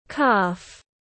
Bắp chân tiếng anh gọi là calf, phiên âm tiếng anh đọc là /kɑːf/.
Calf /kɑːf/